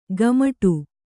♪ gamaṭu